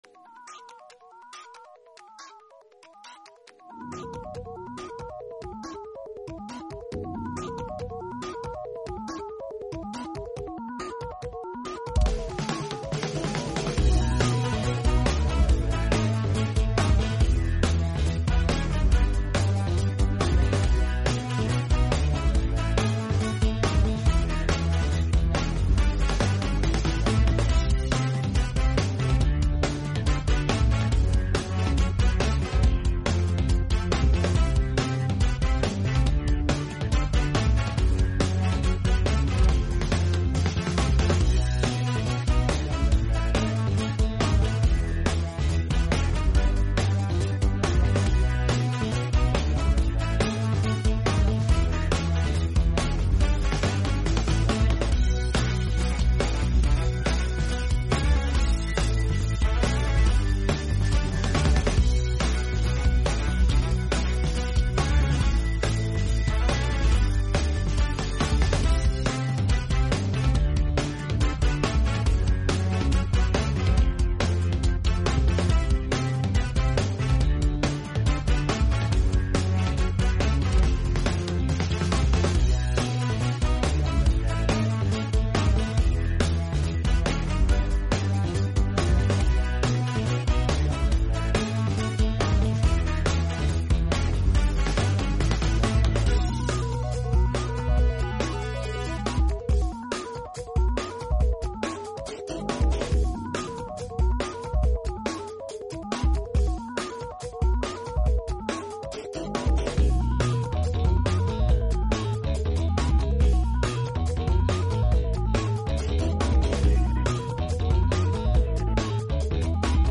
1 Micro Machines video game theme song (which, unbeknownst to most, is actually the sound of Micro Machines Man galoobing slowed down 800%).